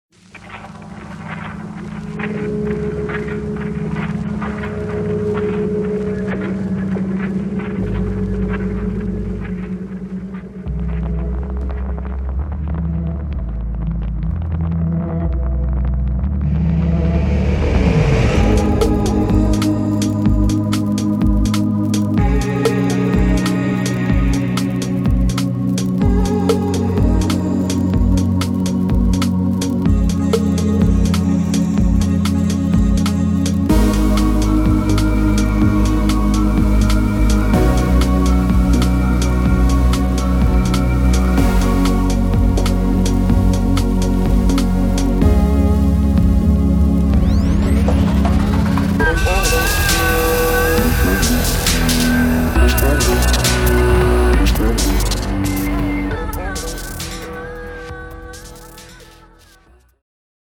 Downtempo
根源与夜生活在这个史诗般的碰撞中相遇，这是一场贯穿繁茂的电影氛围到低调贝斯锻炼的杜比、电子和旅行跳跃的音乐融合。